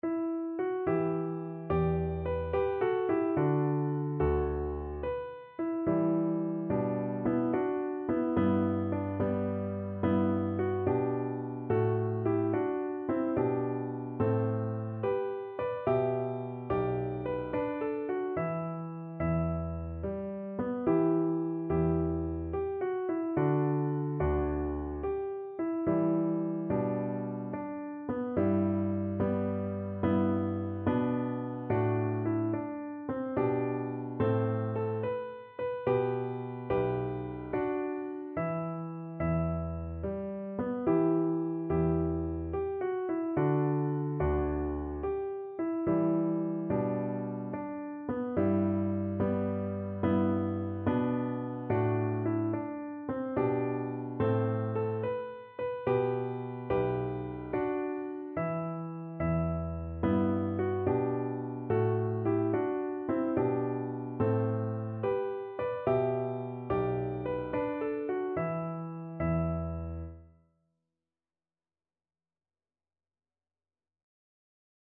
9/8 (View more 9/8 Music)
Gently .=c.72
Traditional (View more Traditional Cello Music)
world (View more world Cello Music)